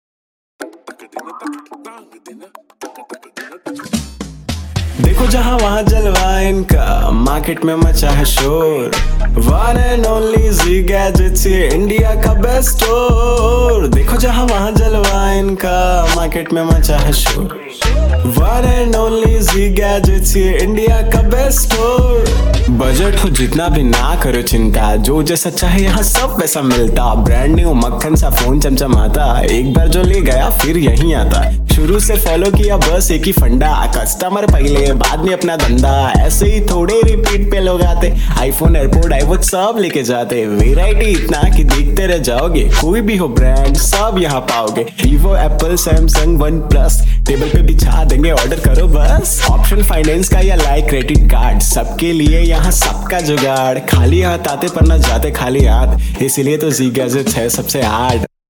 Client Song Example
• Ad Jingles